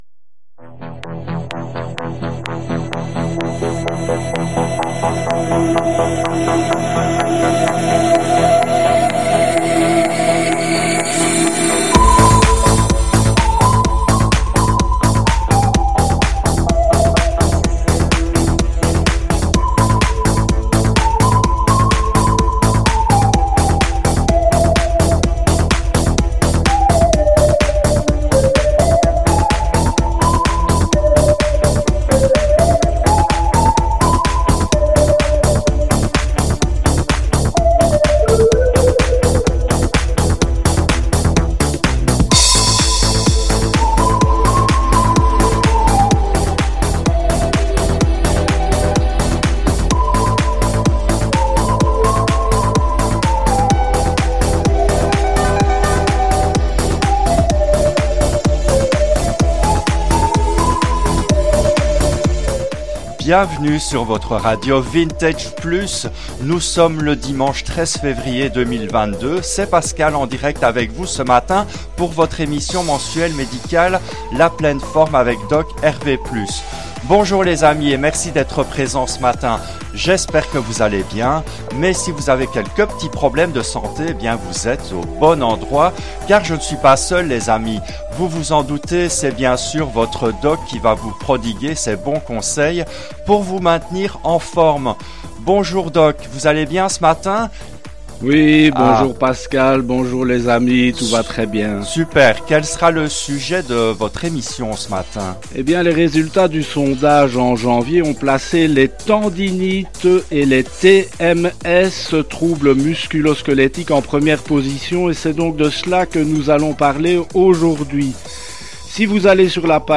Une pathologie moderne abordée en musique et en jeux
C’était en direct le dimanche 13 février 2022 à 10 heures depuis les studios belges de Radio Vintage Plus.